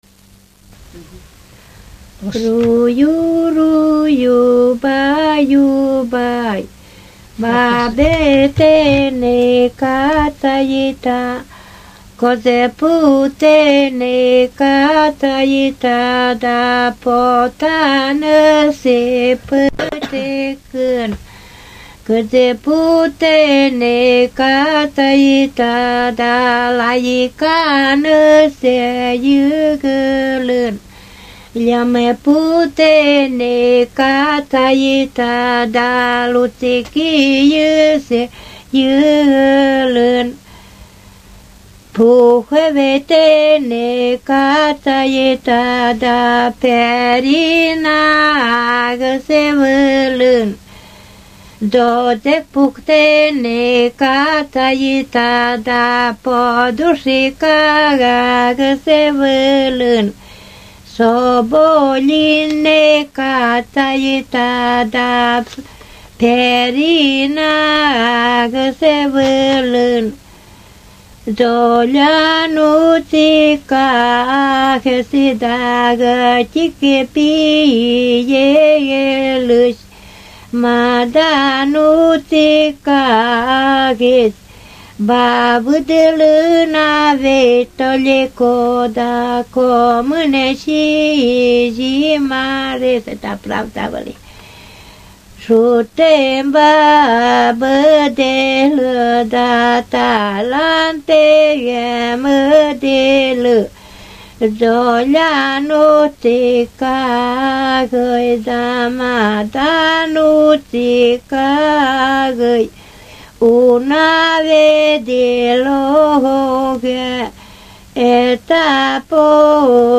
Колыбельные песни и пестушки